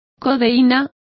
Complete with pronunciation of the translation of codeine.